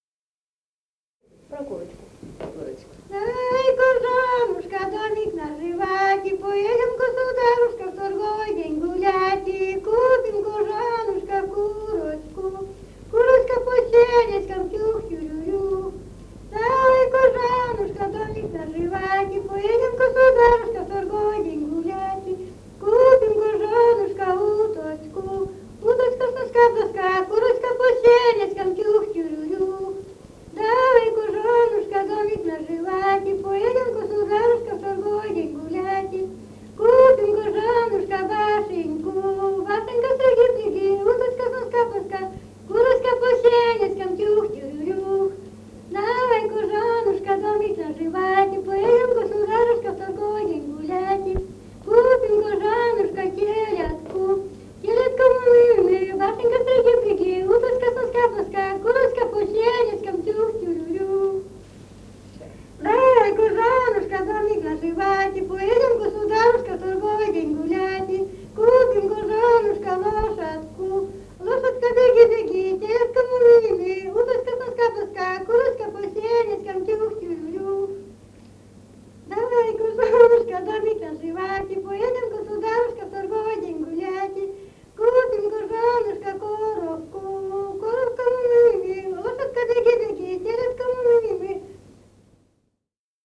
в д. Малата Череповецкого района